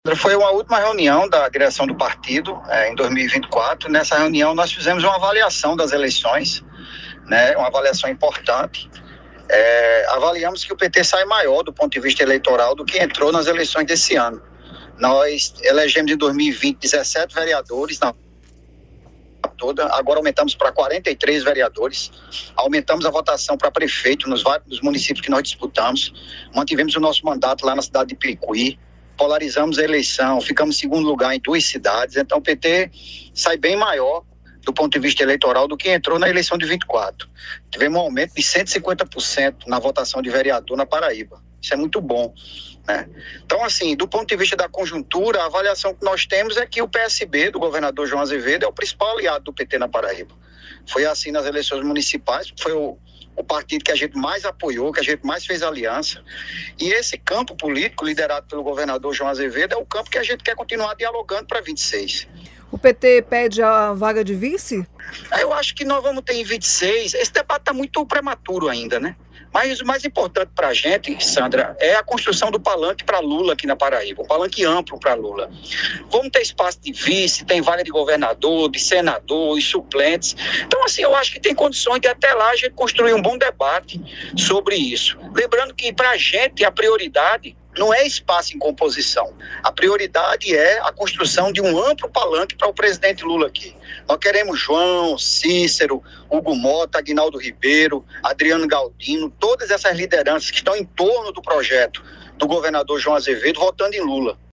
Durante entrevista ao programa Correio Debate, da Rádio Correio 98 FM desta segunda-feira